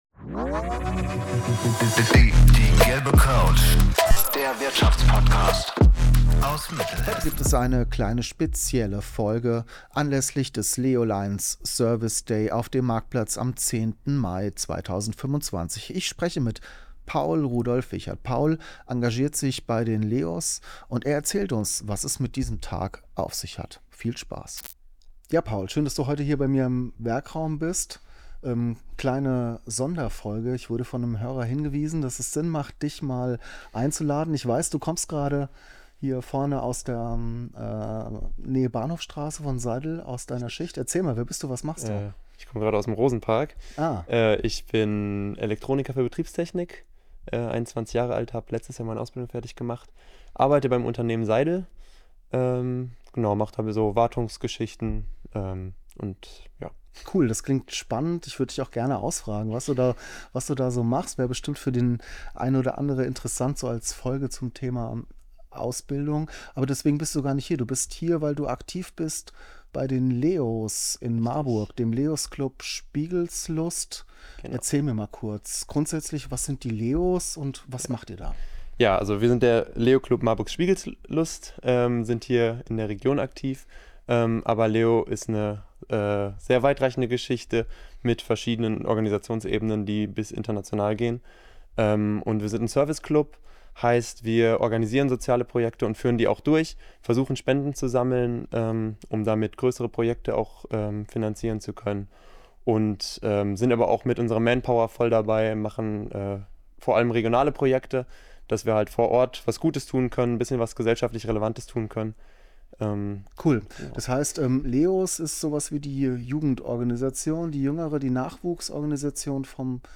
Ein Gespräch über Motivation, Teamgeist, soziale Projekte und die Frage: Wie findet man als junger Mensch einen sinnvollen Weg, sich gesellschaftlich einzubringen?